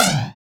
SI2 FLANGE0F.wav